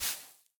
brushing_sand4.ogg